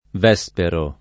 Vespero [ v $S pero ]